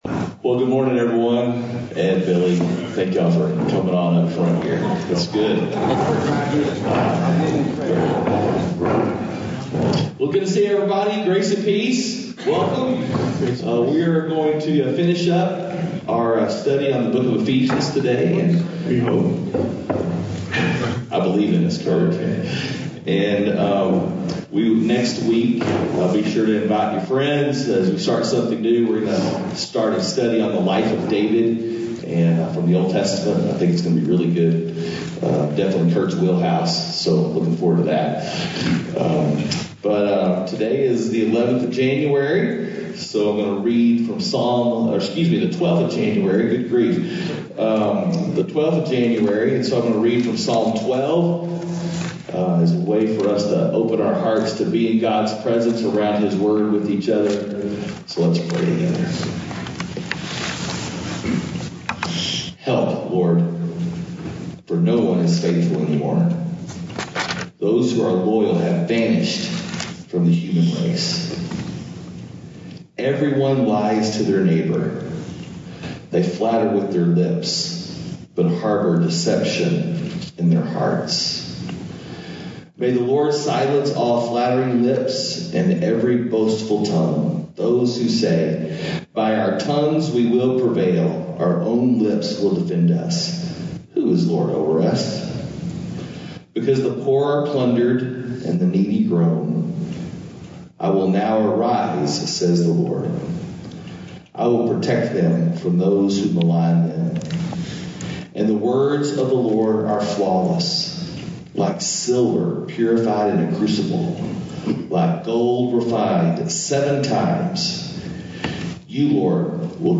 Men’s Breakfast Bible Study 1/12/21
Mens-Breakfast-Bible-Study-1_12_21.mp3